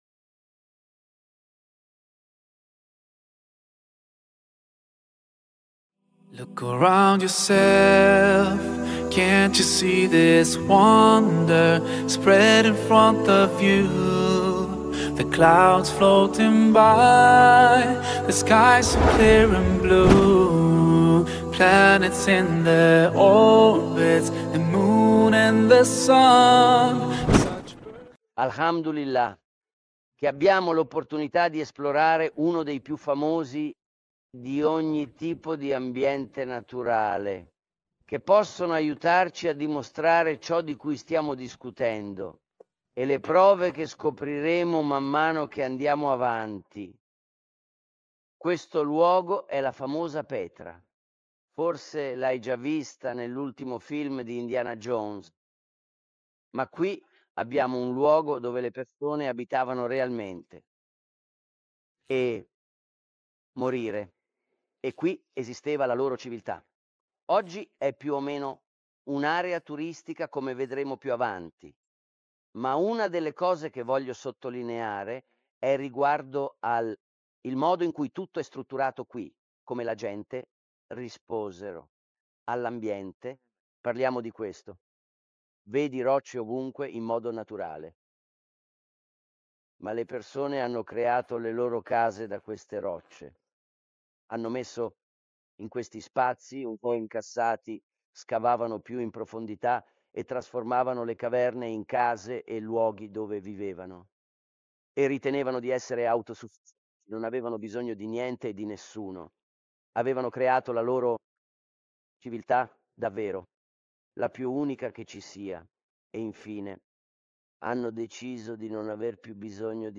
girata tra i paesaggi mozzafiato e i luoghi storici della Giordania. In questo episodio, spiega il primo pilastro della fede: la credenza in Dio.